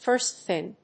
アクセント(the) fírst thìng